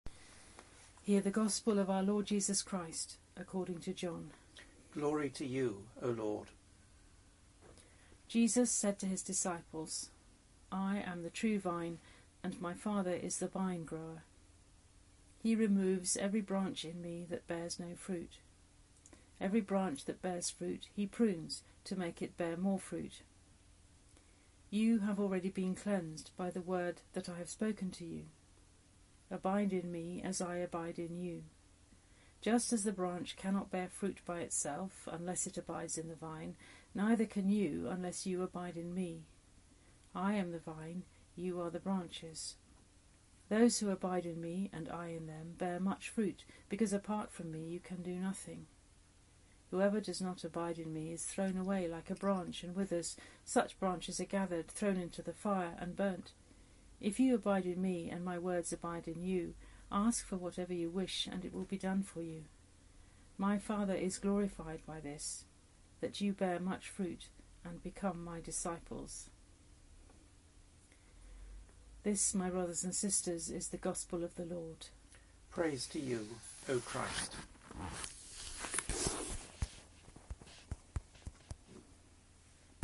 Gospel
Gospel-Reading-for-Easter-5B.mp3